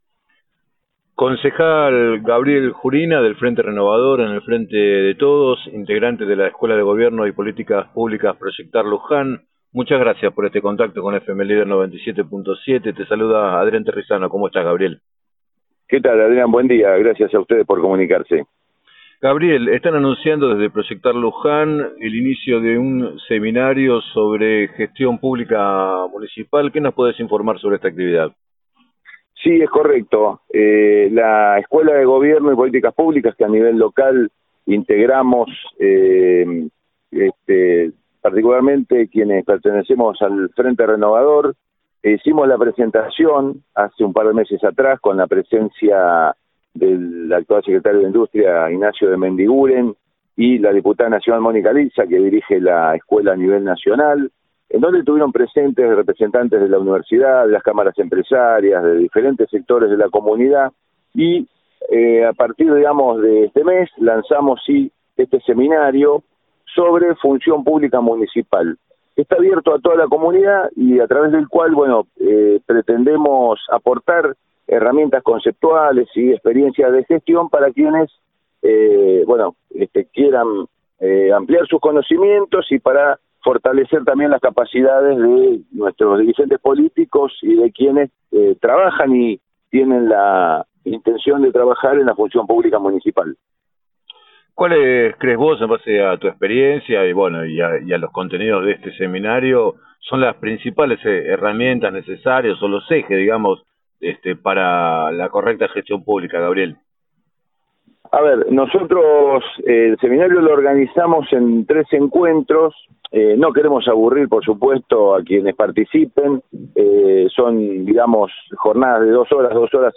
En declaraciones al programa 7 a 9 de FM Líder 97.7, el concejal del Frente Renovador, Gabriel Jurina, quien integra ProyectAR, informó que en el cierre contarán con la participación del diputado nacional Carlos Selva y destacó la importancia de la formación para la gestión municipal.